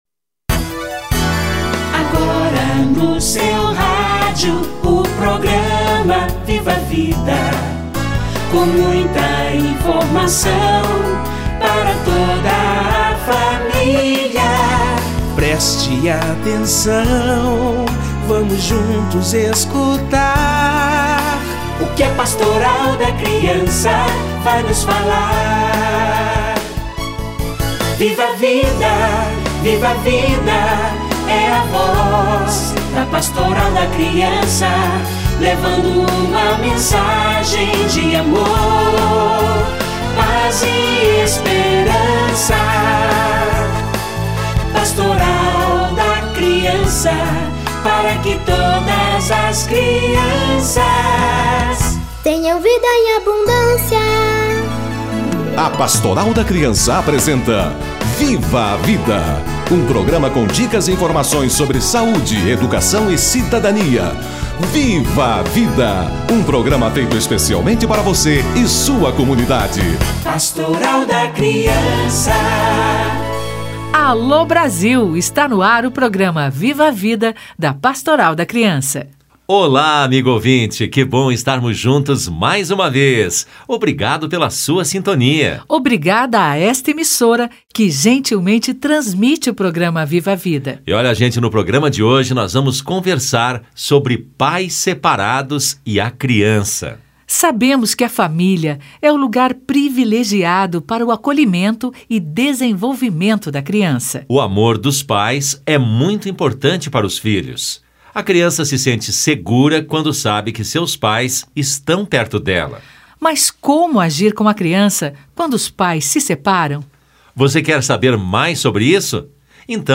Pais separados e a criança - Entrevista